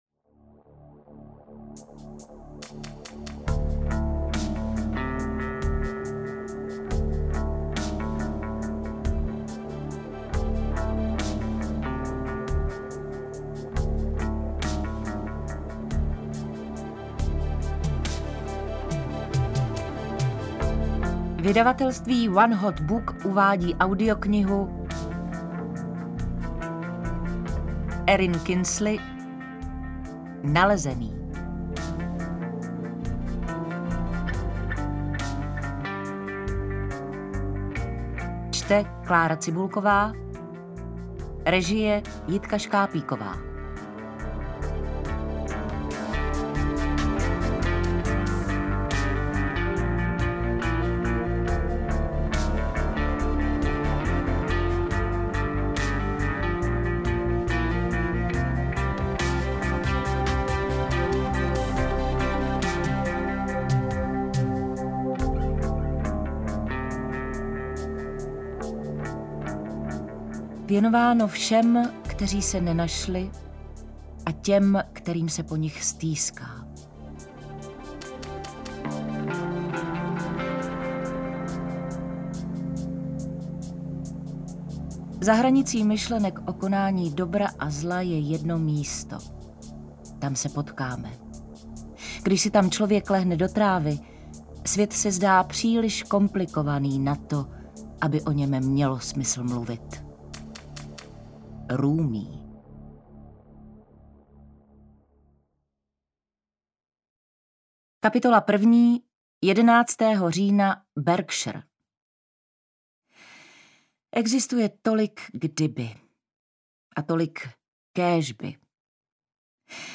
Interpret:  Klára Cibulková
AudioKniha ke stažení, 56 x mp3, délka 11 hod. 15 min., velikost 598,0 MB, česky